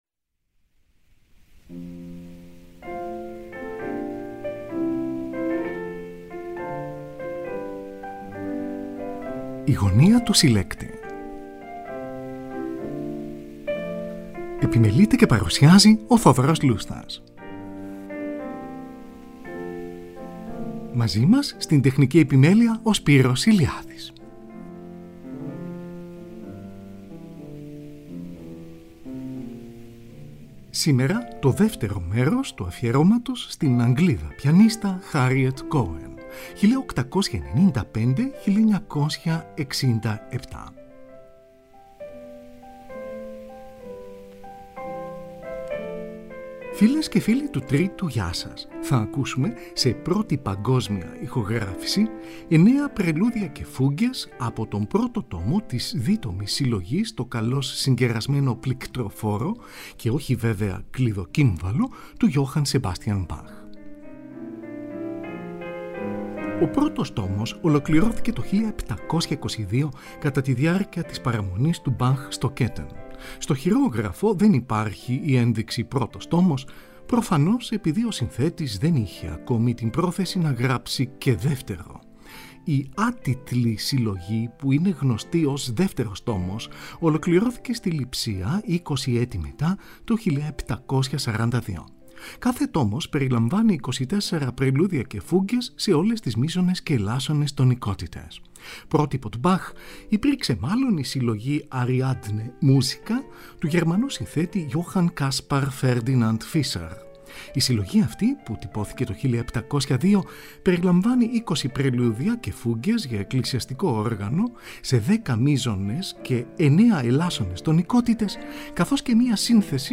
Johann Sebastian Bach: πρελούδια και φούγκες αρ.1-9, από τον πρώτο τόμο της δίτομης συλλογής Το καλώς συγκερασμένο πληκτροφόρο, σε πρώτη παγκόσμια ηχογράφηση.